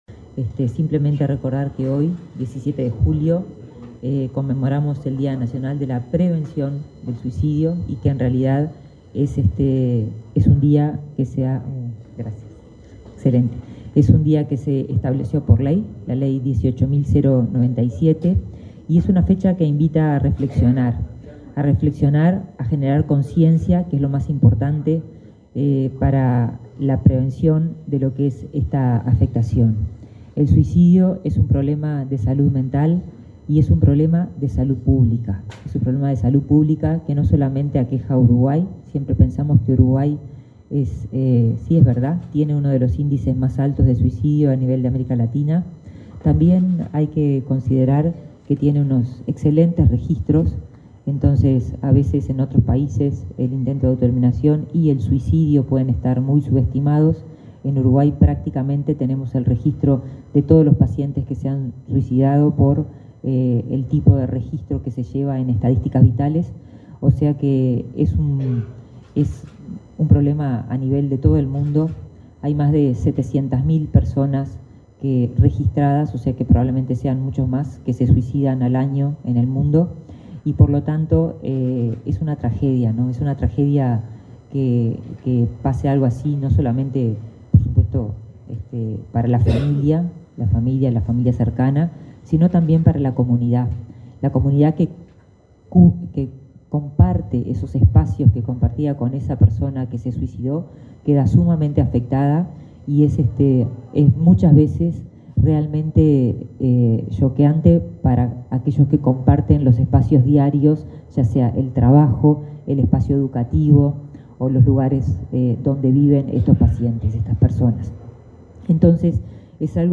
Palabras de autoridades en acto en el MSP
Palabras de autoridades en acto en el MSP 17/07/2024 Compartir Facebook X Copiar enlace WhatsApp LinkedIn La titular del Ministerio de Salud Pública (MSP), Karina Rando; su par de Desarrollo Social, Alejandro Sciarra, y el subsecretario del MSP, José Luis Satdjian, participaron, este miércoles 17 en la sede de esa cartera, en el acto de lanzamiento de una guía práctica para el abordaje del suicidio.